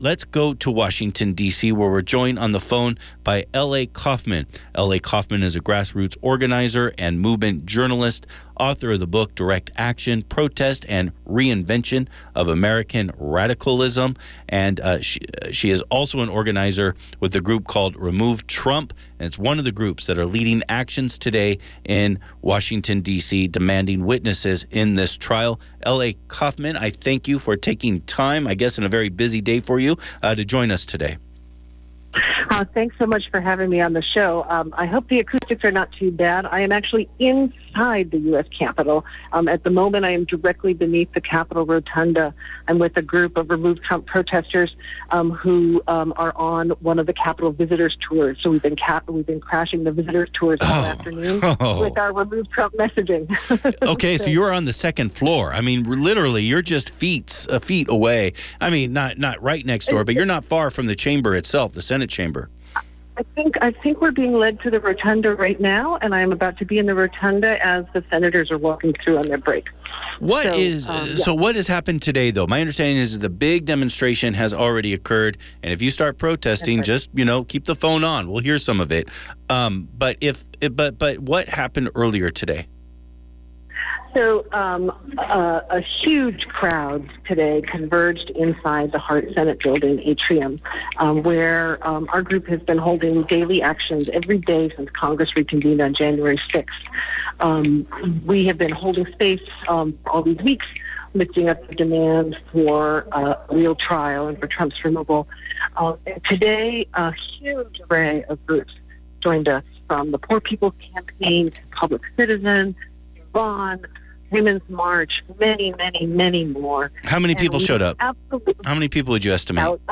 Special Broadcast
12:45  pm – Guest analysis: